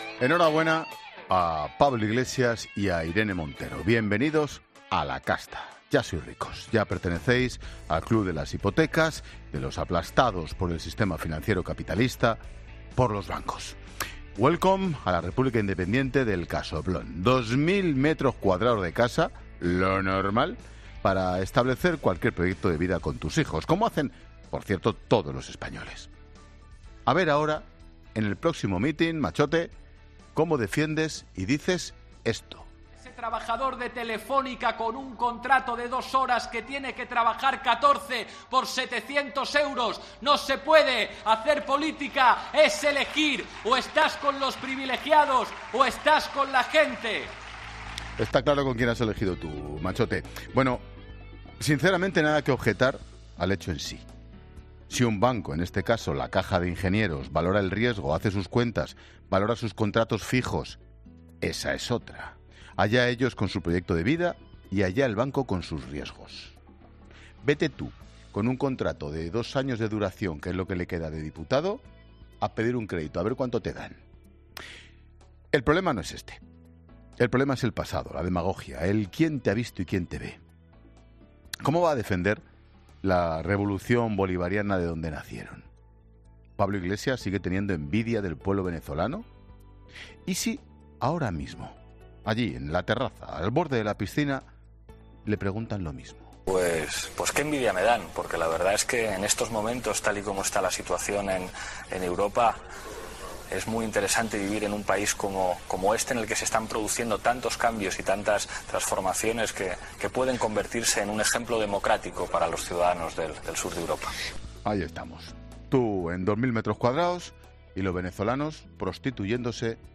Monólogo de Expósito
En el programa, Expósito ha incluido un corte de audio donde Iglesias pedía hace años que la gente se posicionara: o estabas con los privilegiados o con la gente.